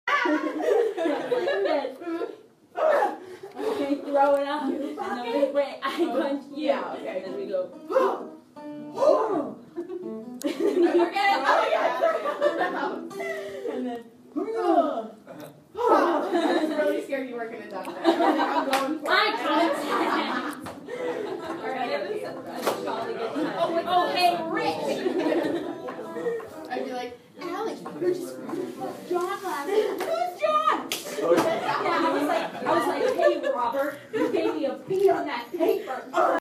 Field Recording
Lowe 106, during a break at Peer Gynt rehearsals People talking about classes and doing exercises
Peer-Gynt-Rehearsal.mp3